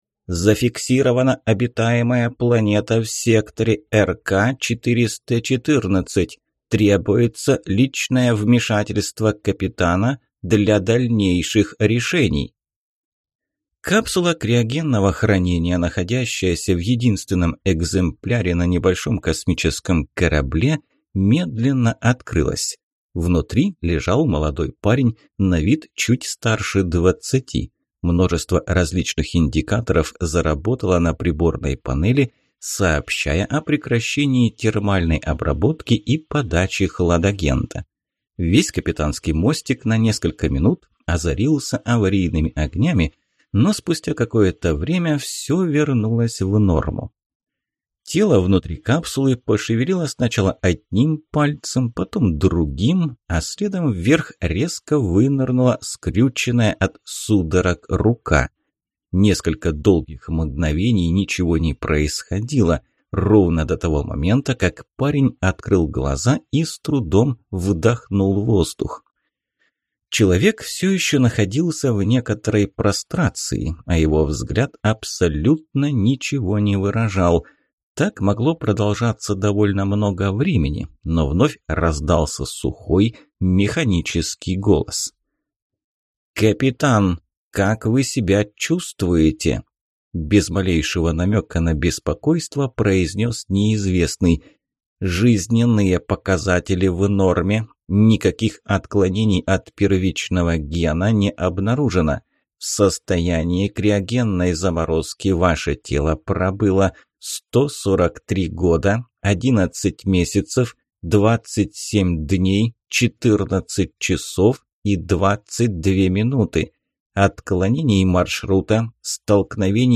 Аудиокнига Моя Космическая Станция. Книга 1 | Библиотека аудиокниг